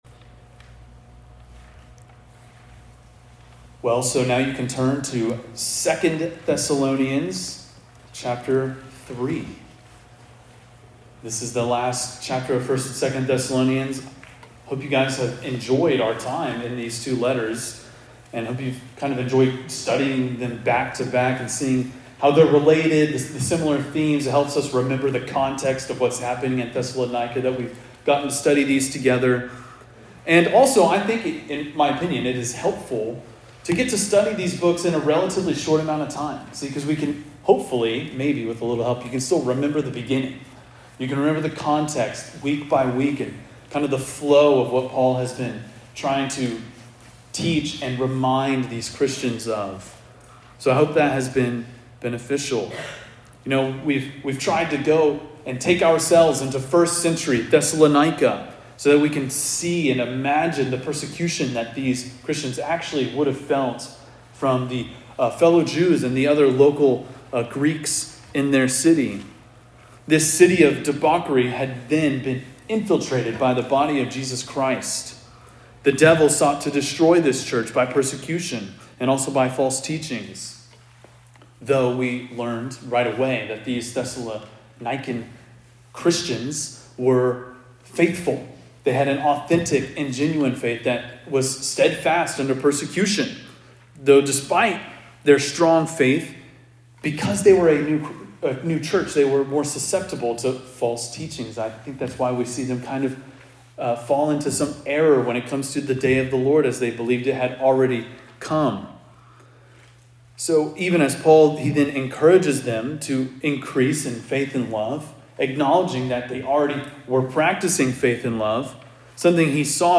preaches through the final chapter of 2 Thessalonians.&nbsp